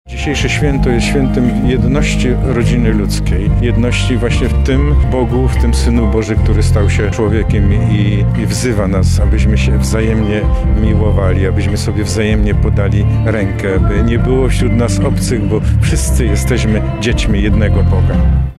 • mówi metropolita lubelski, arcybiskup Stanisław Budzik.